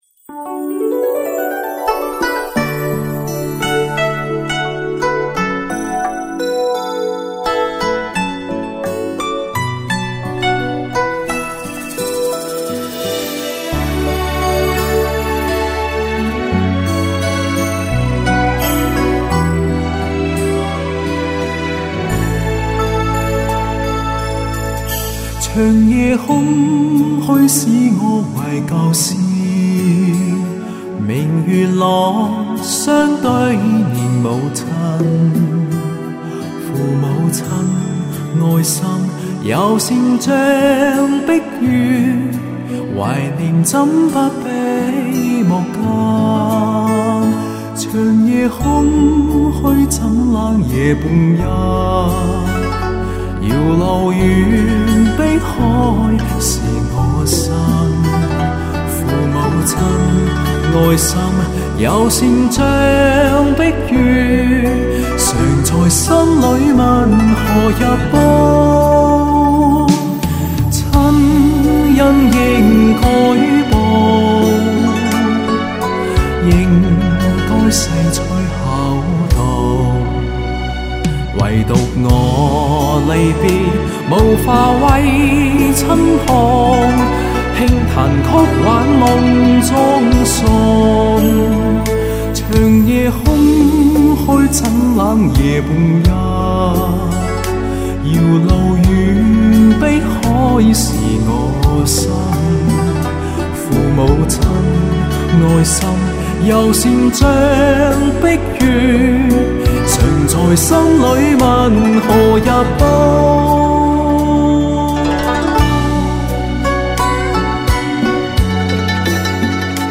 录音设备还是 SE RNT 和PRISMSONUD 天琴座自带的话放
电子管EQ 就是飞来音介绍过插件版本那个 PULTEC 看图 外加上硬件的 CL1B
没想到这么一过 一股子软糯酥滑 丰腴肥润感、扑面而来 综合了一点 RNT的硬朗 来的是那么恰到好处